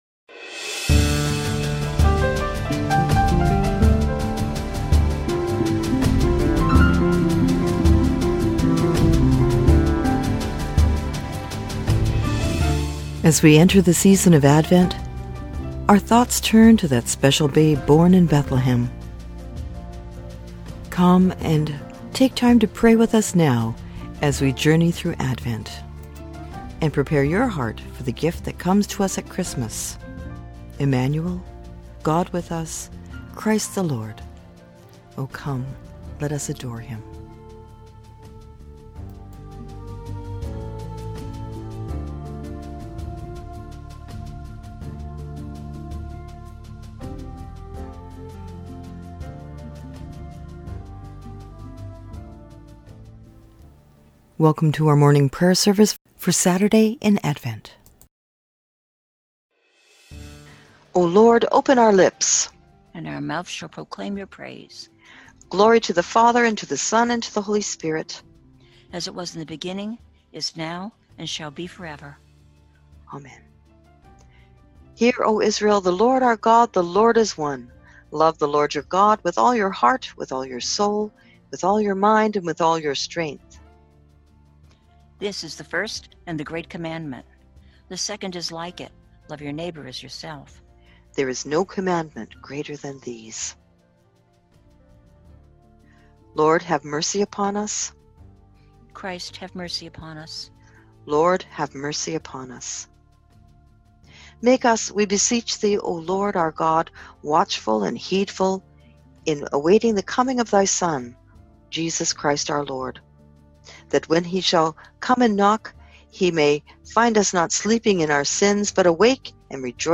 Here is today’s prayer service.
6satmorningadvent.mp3